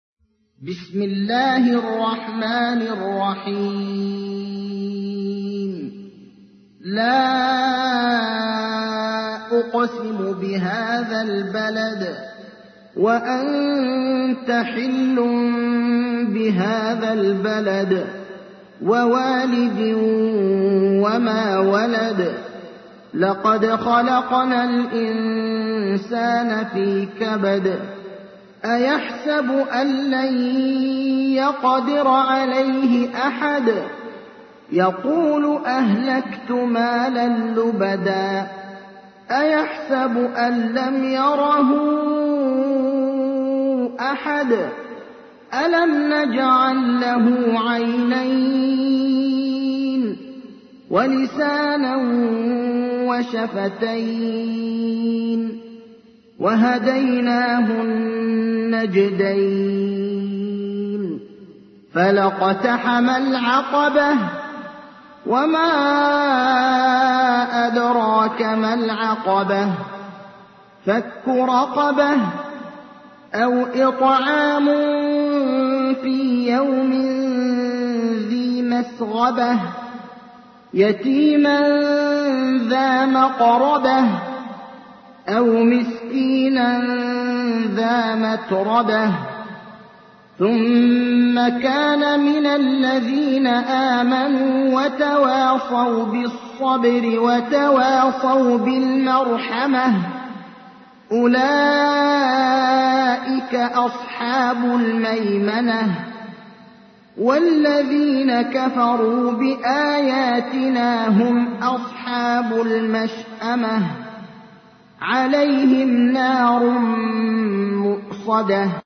تحميل : 90. سورة البلد / القارئ ابراهيم الأخضر / القرآن الكريم / موقع يا حسين